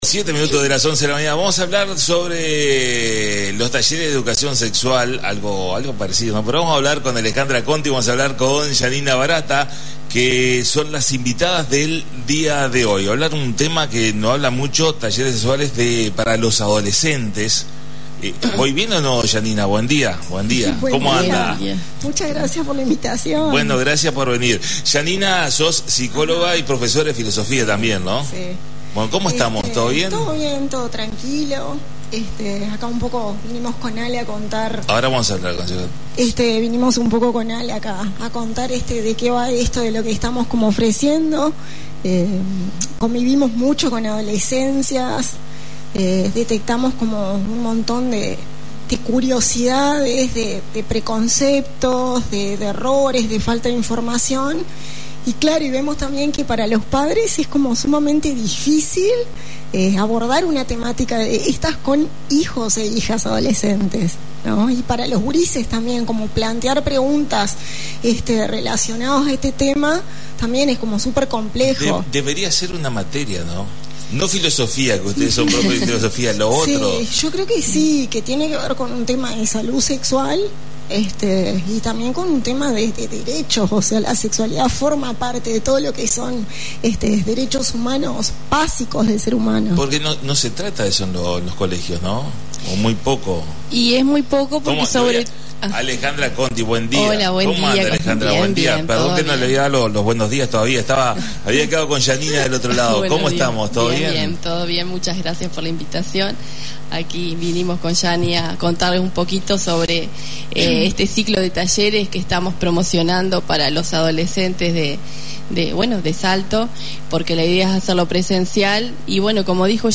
La visita a la Radio